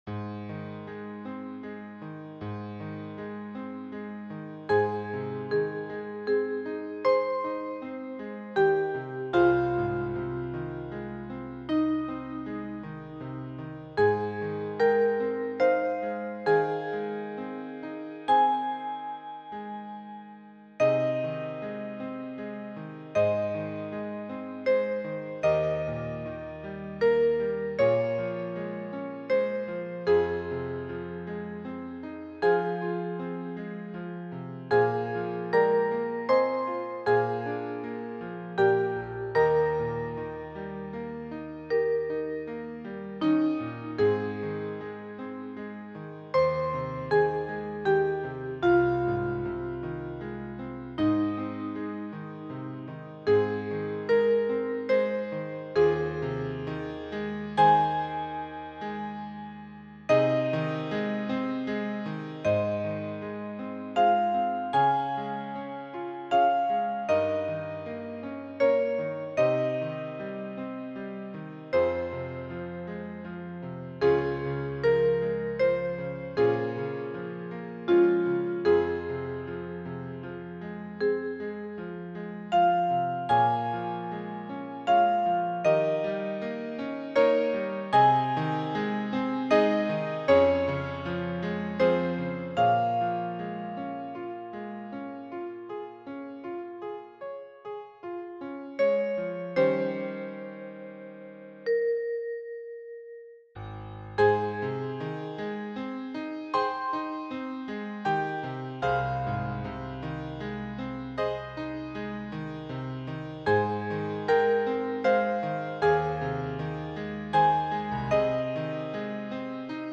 Vibraphone (Xylophone) und Klavier